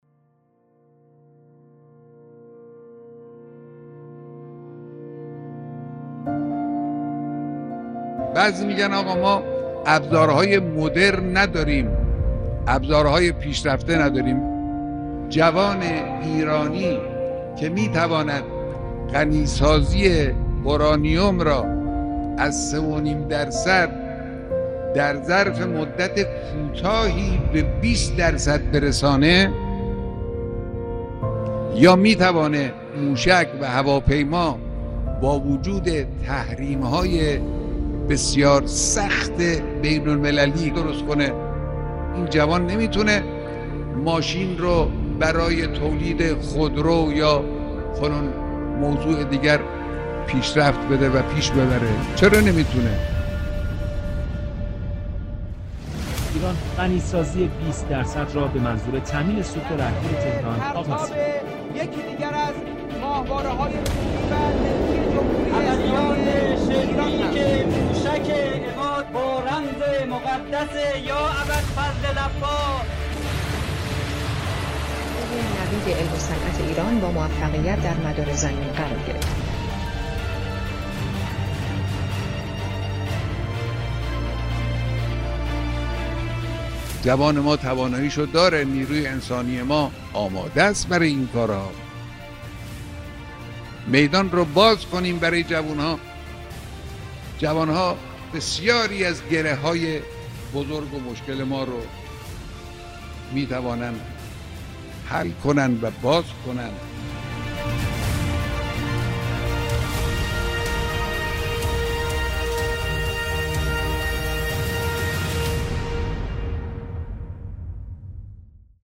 صداهنگ